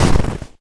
High Quality Footsteps
STEPS Snow, Run 22.wav